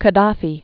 (kə-däfē) or Gad·da·fi (gə-), Muammar al- or el- 1942-2011.